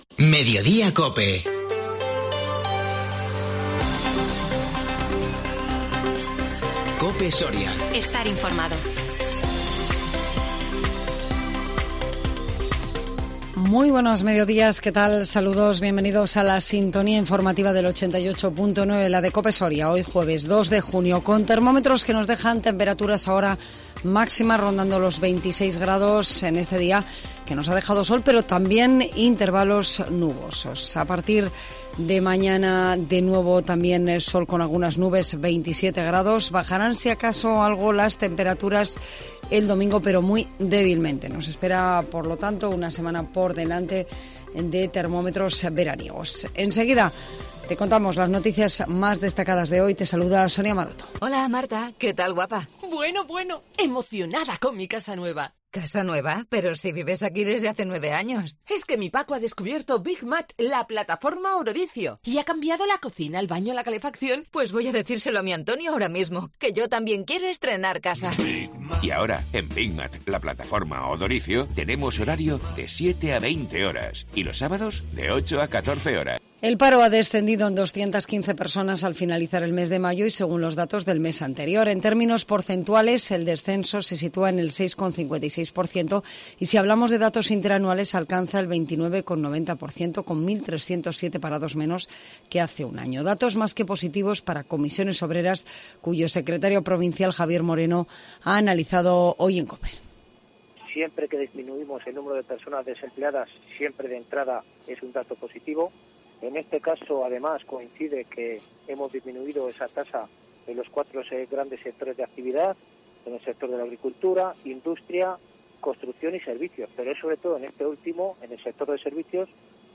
INFORMATIVO MEDIODÍA COPE SORIA 2 JUNIO 2022